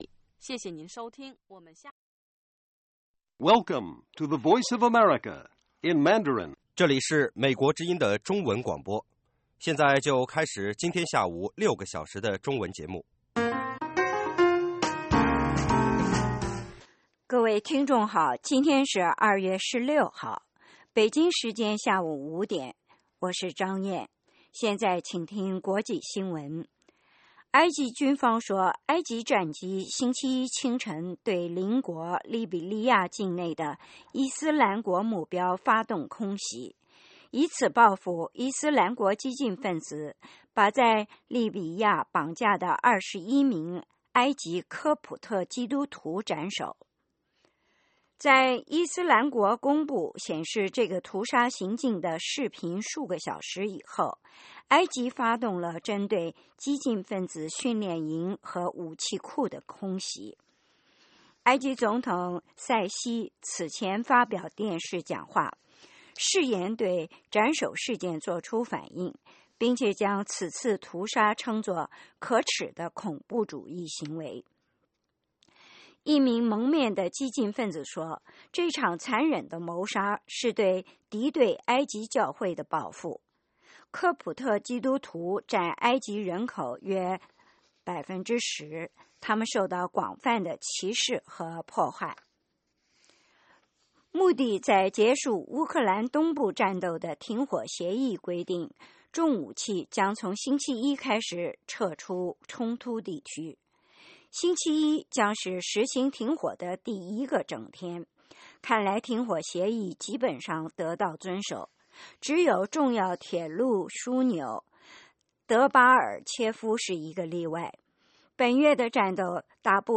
北京时间下午5-6点广播节目。 内容包括国际新闻和美语训练班（学个词， 美国习惯用语，美语怎么说，英语三级跳， 礼节美语以及体育美语）